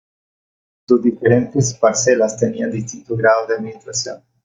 Pronounced as (IPA) /ˈɡɾados/